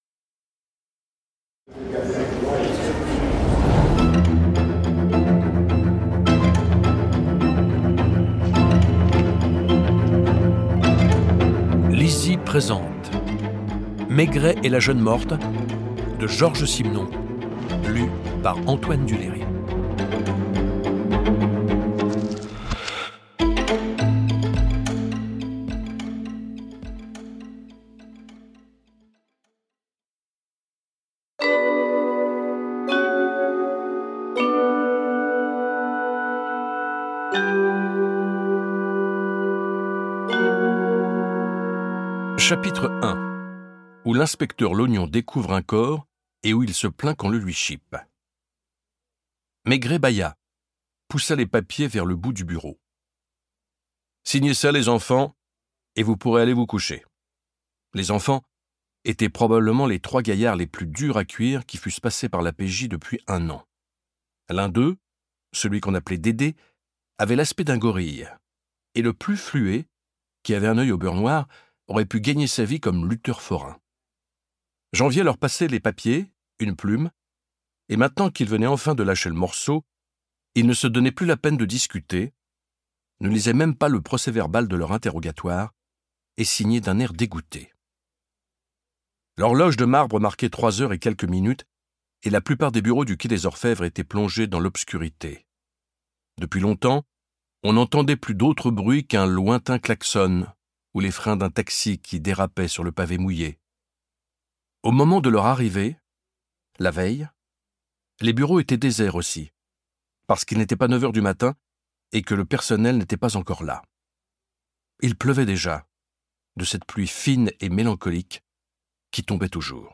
Click for an excerpt - Maigret et la jeune morte de Georges Simenon